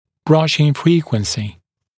[tuːθ ‘brʌʃɪŋ ‘friːkwənsɪ][ту:с ‘брашин ‘фри:куэнси]частота чистки зубов